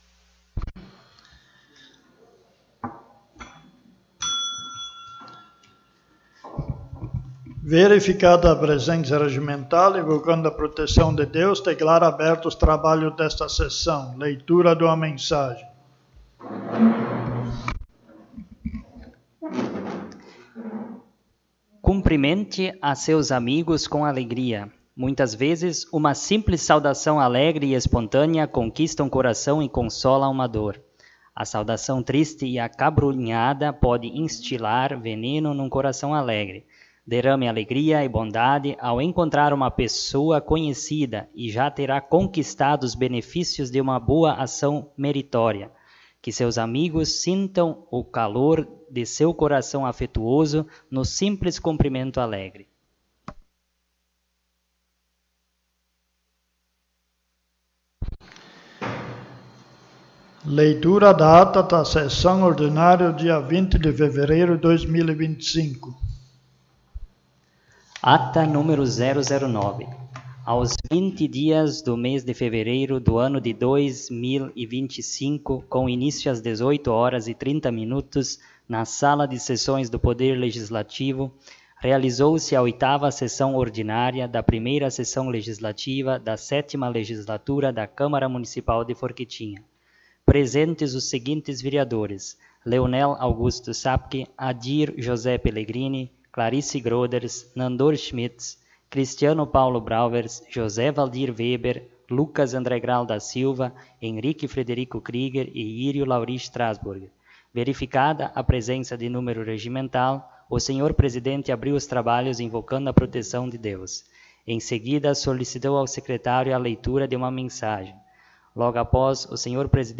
9ª Sessão Ordinária